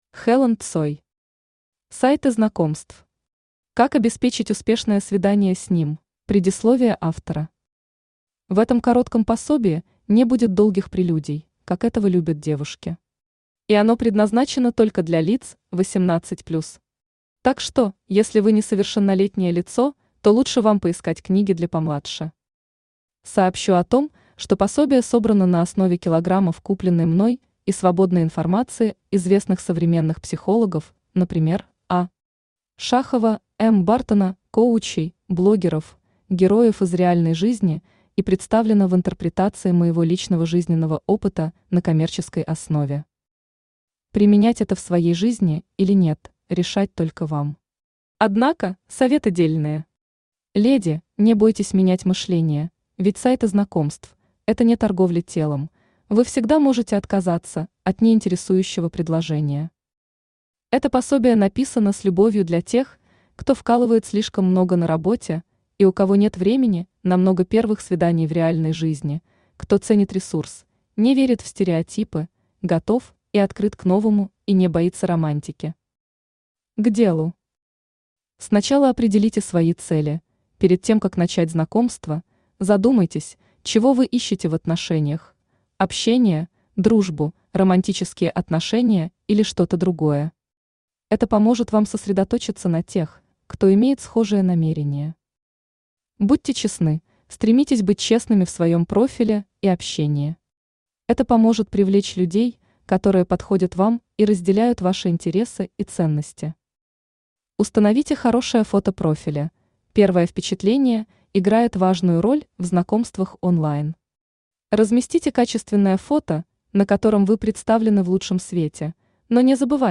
Аудиокнига Сайты знакомств. Как обеспечить успешное свидание с ним?
Автор Helen Tsoy Читает аудиокнигу Авточтец ЛитРес.